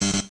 Buzz Error (5).wav